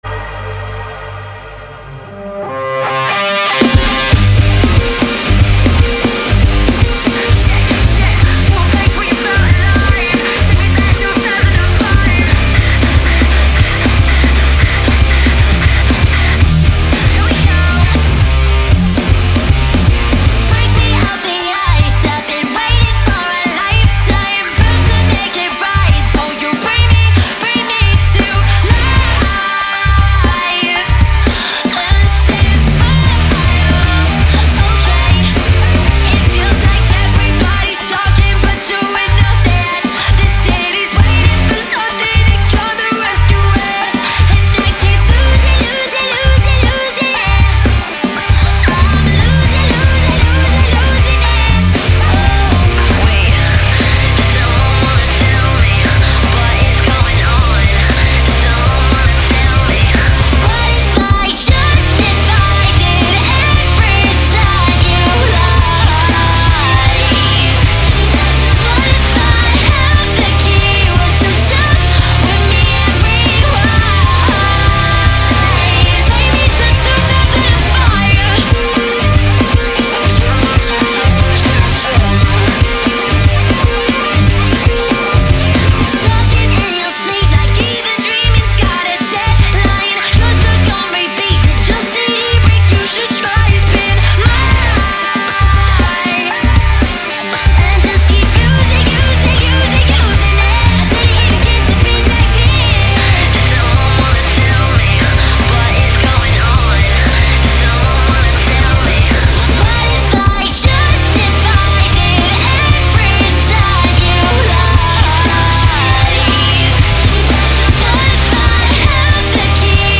BPM117-117
Audio QualityPerfect (High Quality)
Pop Punk song for StepMania, ITGmania, Project Outfox
Full Length Song (not arcade length cut)